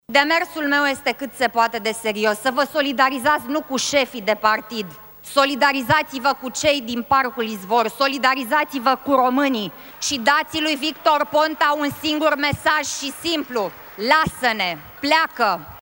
In schimb, co-președintele PNL Alina Gorghiu a subliniat că trimiterea în judecată a premierului Victor Ponta aduce prejudicii grave României și i-a îndemnat pe membrii Legislativului să voteze moțiunea:
Camera Deputaţilor şi Senatul s-au reunit, azi, pentru a dezbate a patra moţiune de cenzură împotriva Guvernului Ponta.